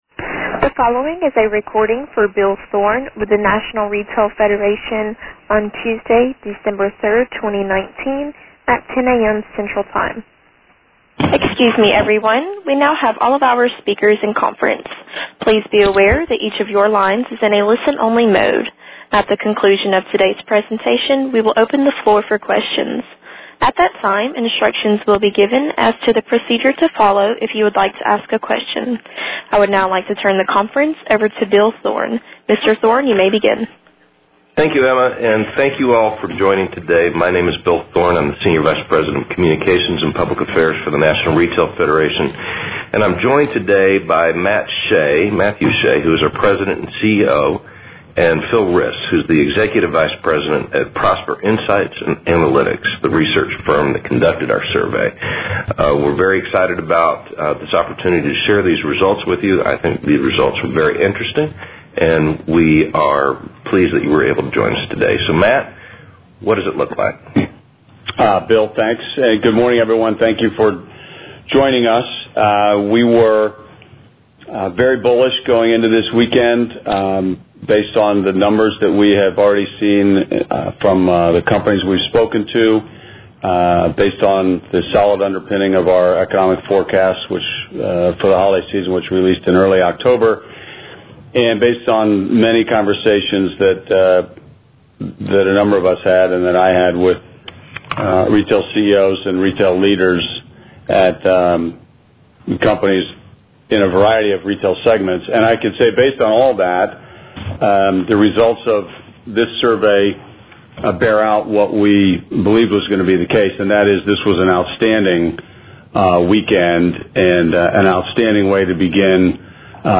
Listen to the media call covering results from the Thanksgiving weekend period here.